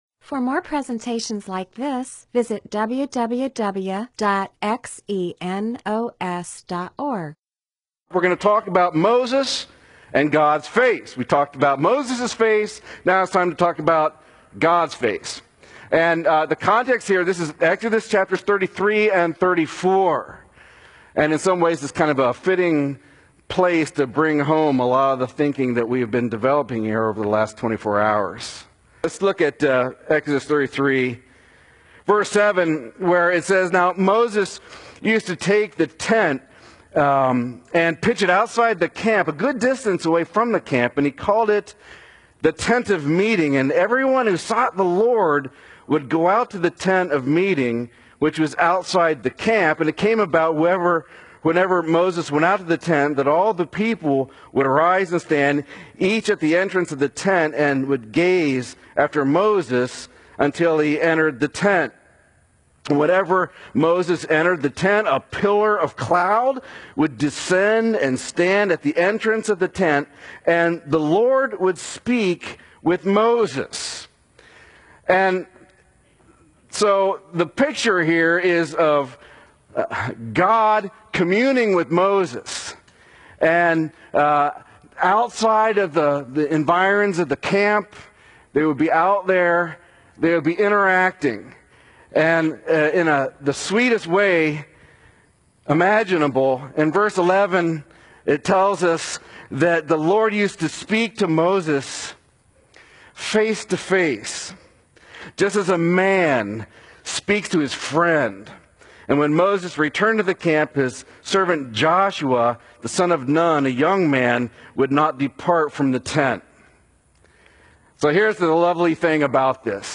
MP4/M4A audio recording of a Bible teaching/sermon/presentation about Exodus 33-34.